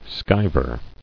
[skiv·er]